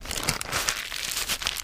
High Quality Footsteps / Soft Plastic / Misc
MISC Soft Plastic, Scrape 01.wav